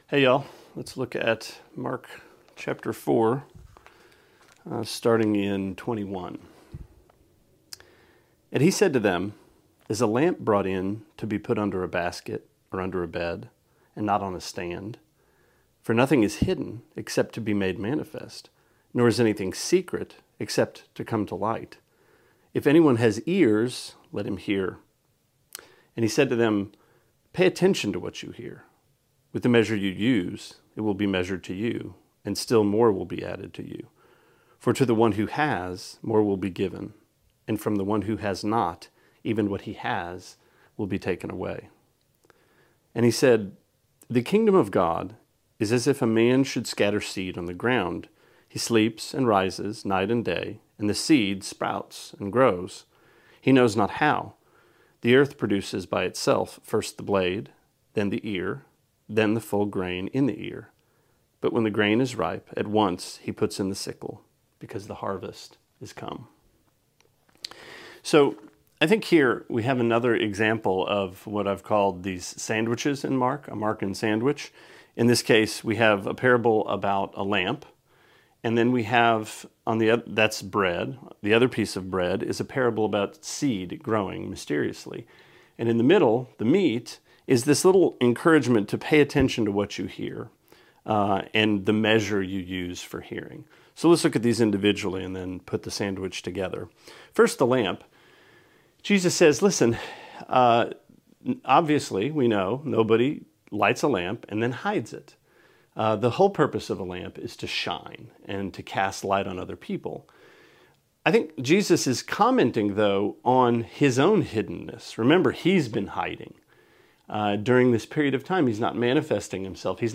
Sermonette 6/28: Mark 4:21-29: Lamps & Seed